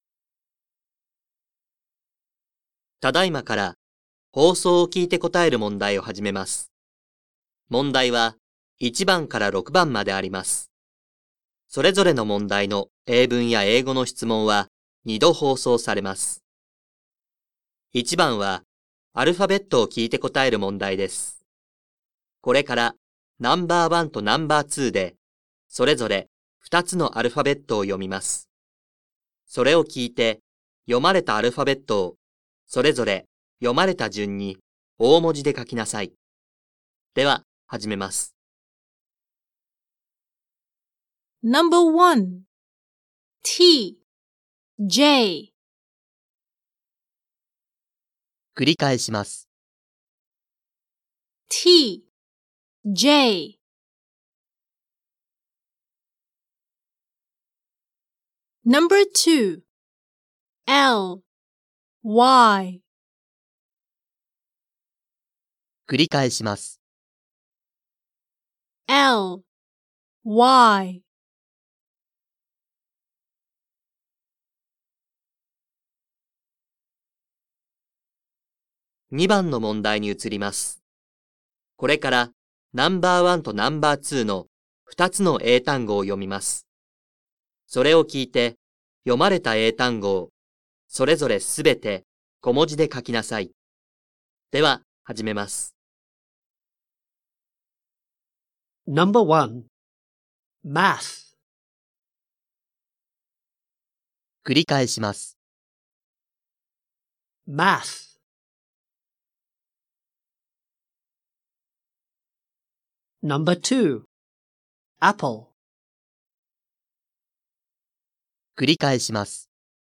2025年度１年１号英語のリスニングテストの音声